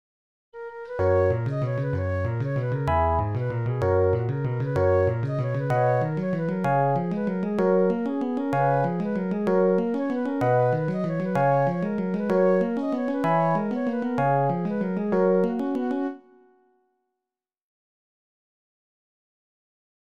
Se puede observar que la melodía es muy sencilla y la comparo con una melodía zapoteca que es interpretada en una flauta de carrizo con acompañamiento de tambor.
Zapoteca